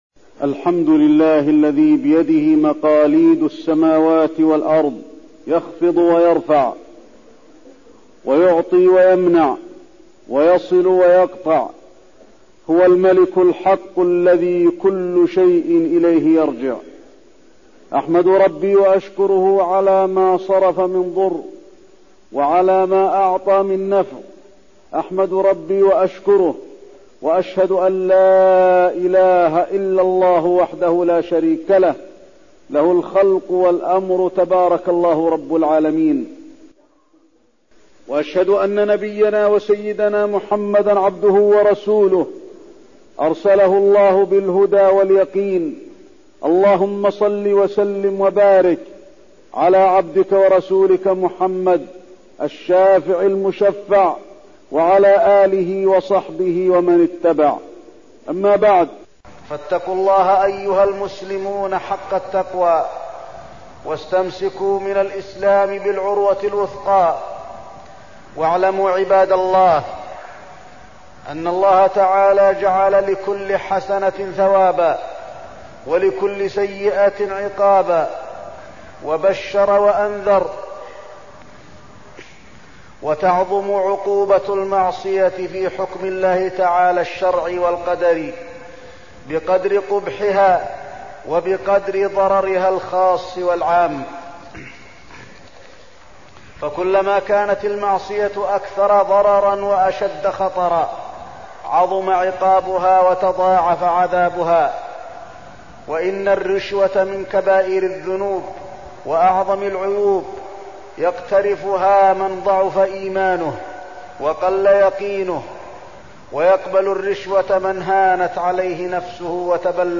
تاريخ النشر ٢٠ ربيع الثاني ١٤١٦ هـ المكان: المسجد النبوي الشيخ: فضيلة الشيخ د. علي بن عبدالرحمن الحذيفي فضيلة الشيخ د. علي بن عبدالرحمن الحذيفي الرشوة